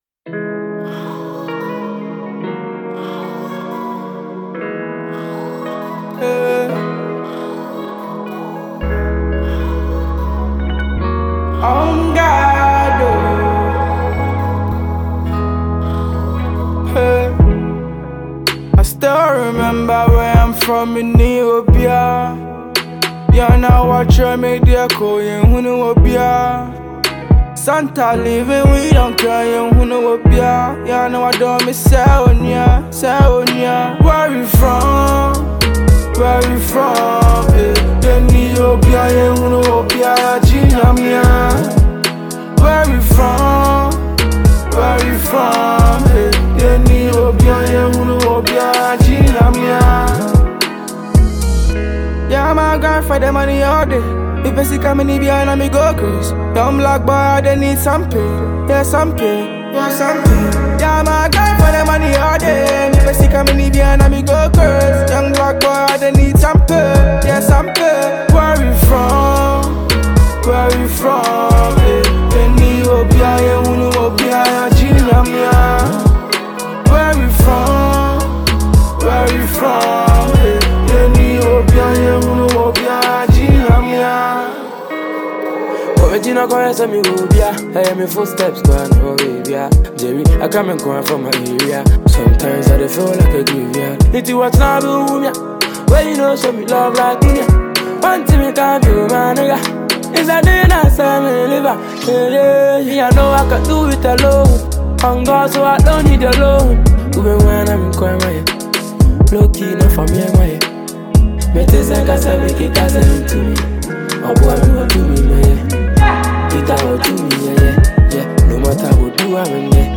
Prominent Ghanaian rapper